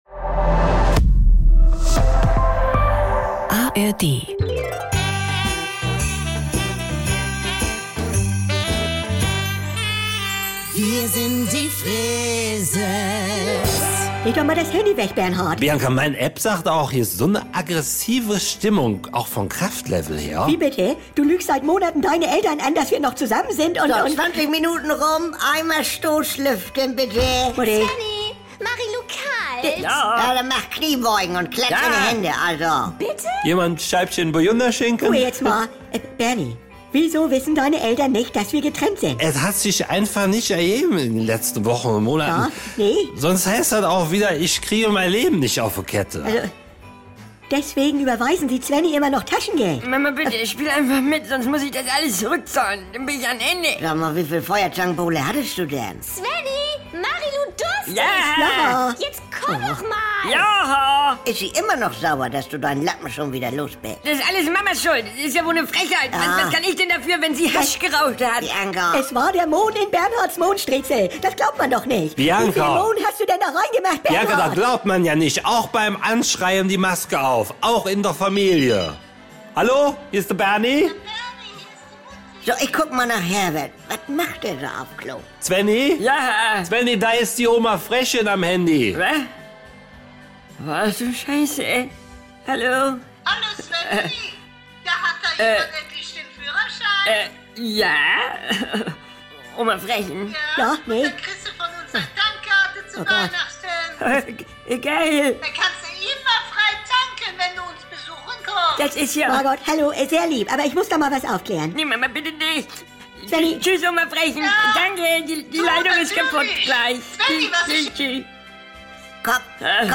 Saubere Komödien NDR 2 Komödie Unterhaltung